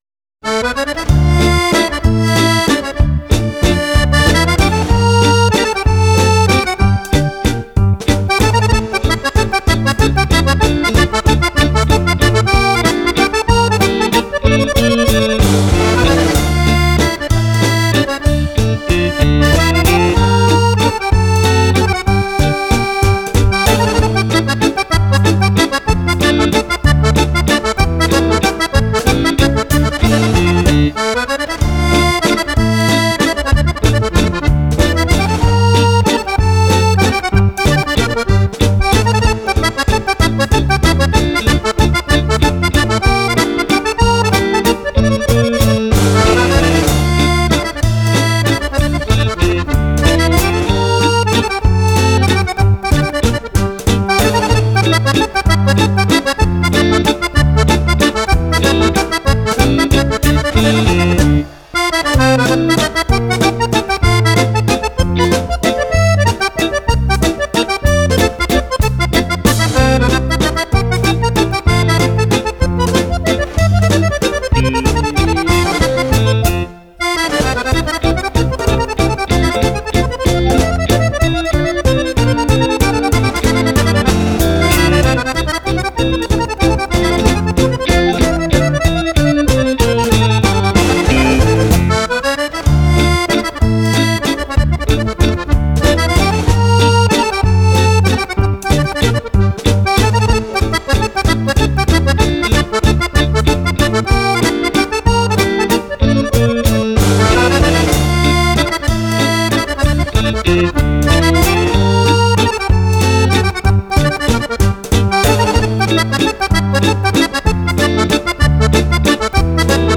Valzer
2 Ballabili per Fisarmonica
LISCIO ALLA VECCHIA MANIERA